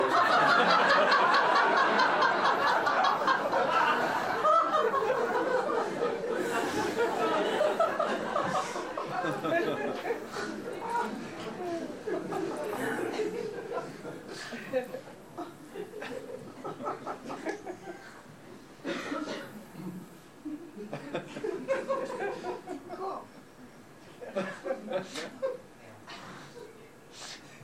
haunted sounds » female demon laugh
标签： female laughter demonic
声道立体声